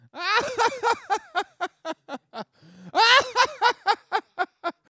Category 🤣 Funny
evil laughing sound effect free sound royalty free Funny